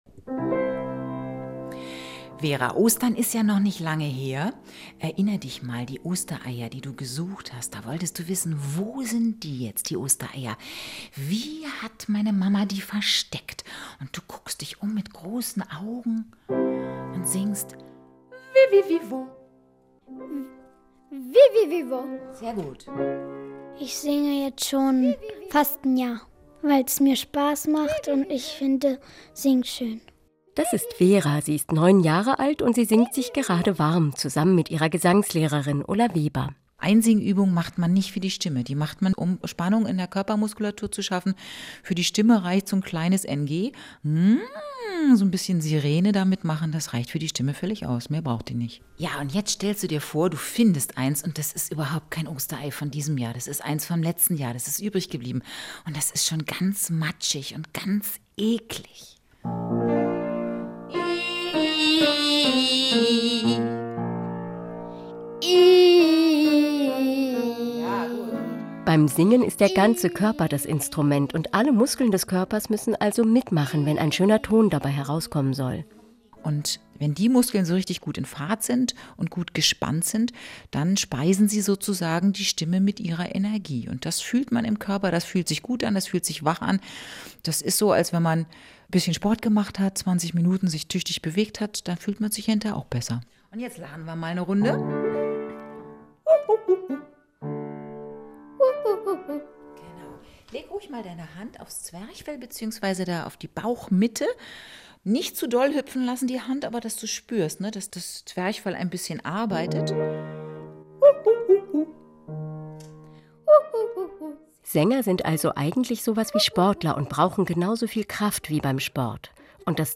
RBB-Interview Singen mit Kindern